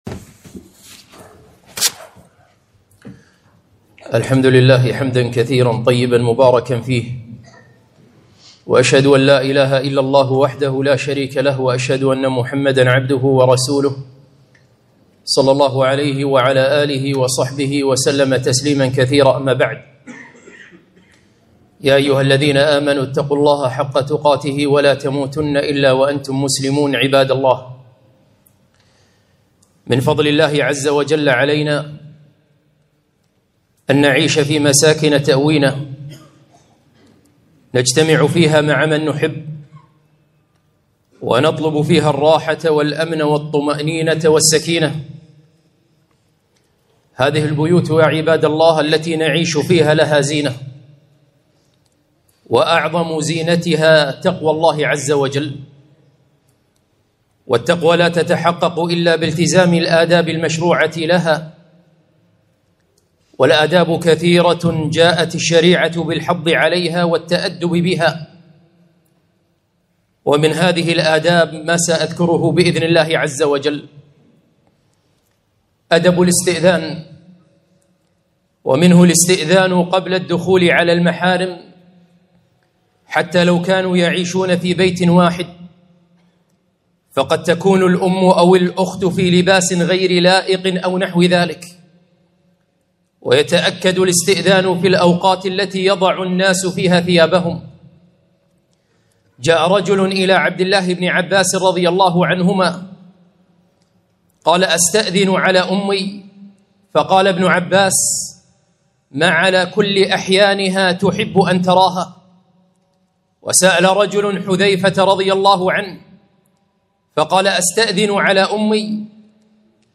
خطبة - من آداب البيوت